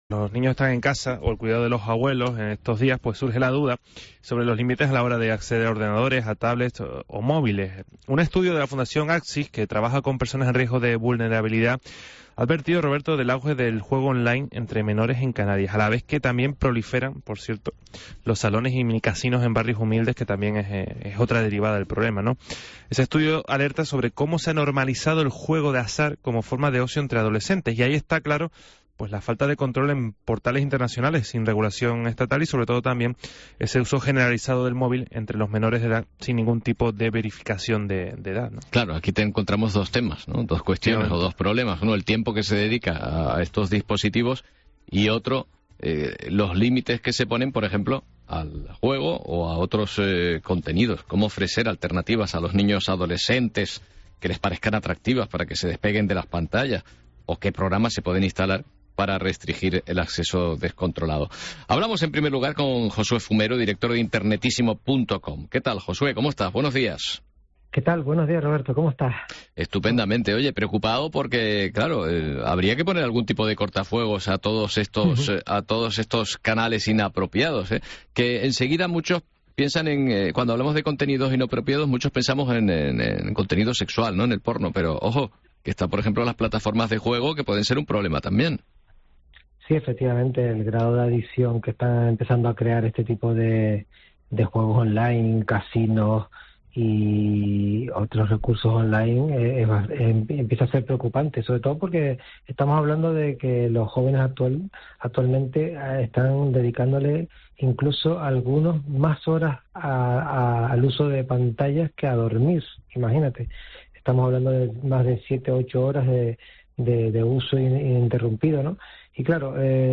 Esta semana, en el programa La mañana de COPE Canarias, hablamos sobre esta situación y cómo imponer medidas de restricción para los niños.